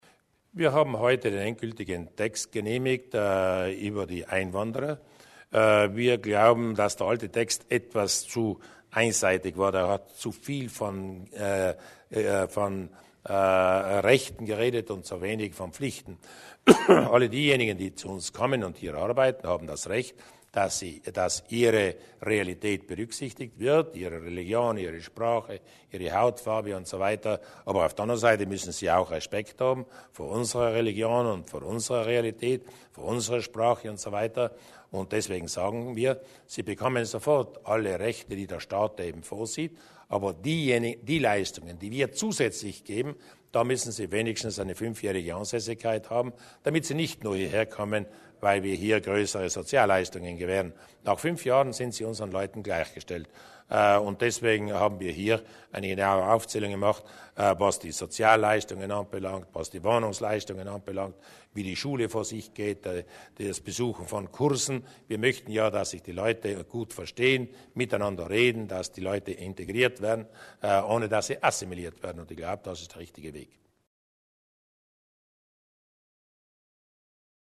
Landeshauptmann Durnwalder zum Einwanderungsgesetz